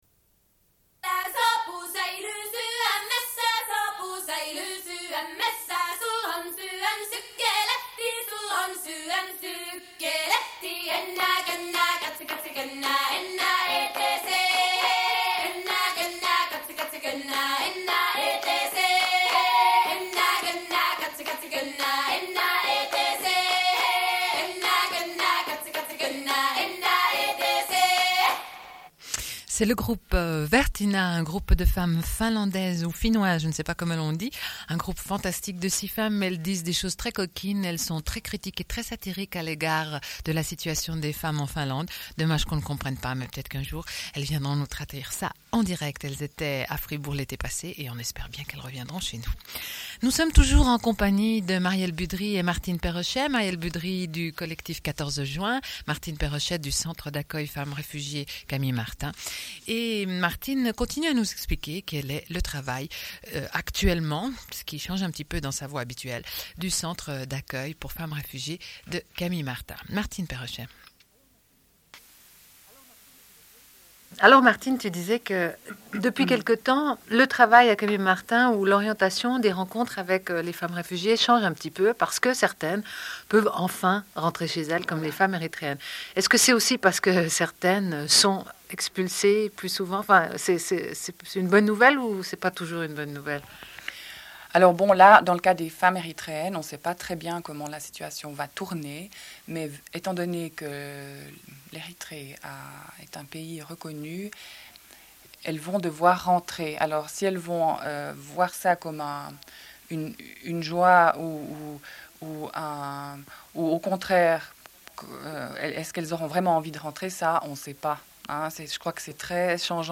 Une cassette audio, face B29:31